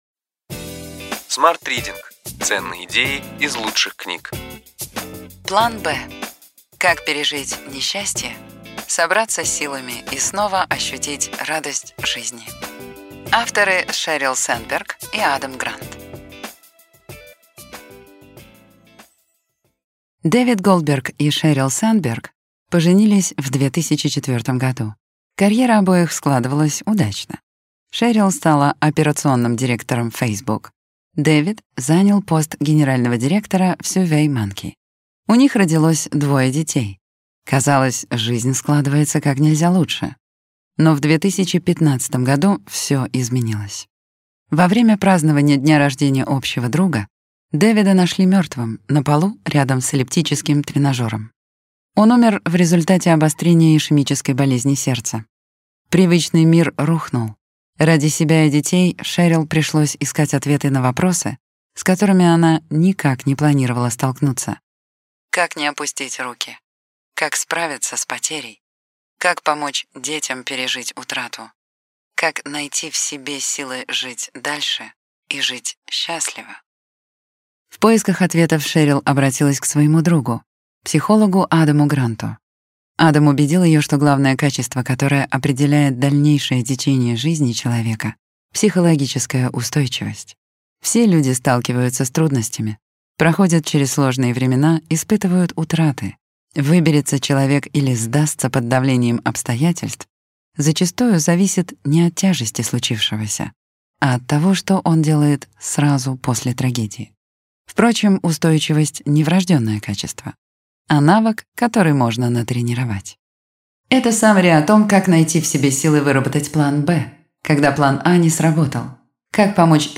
Аудиокнига Ключевые идеи книги: План Б. Как пережить несчастье, собраться с силами и снова ощутить радость жизни.